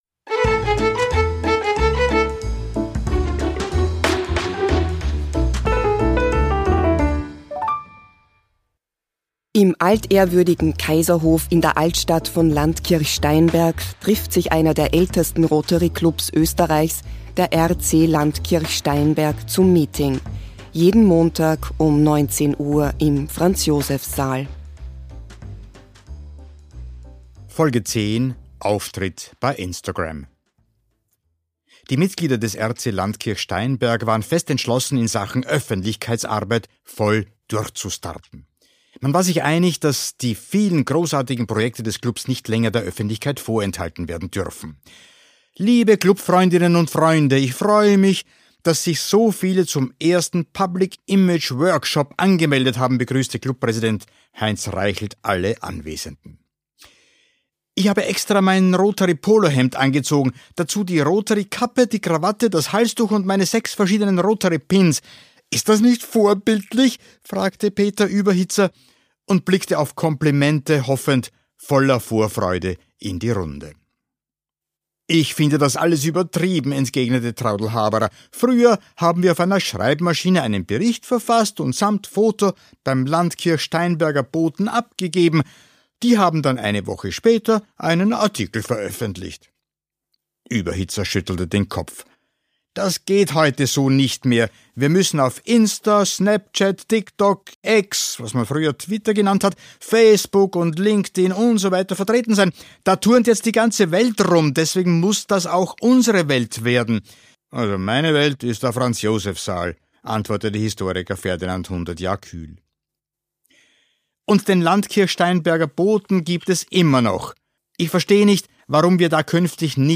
Audio-Comedy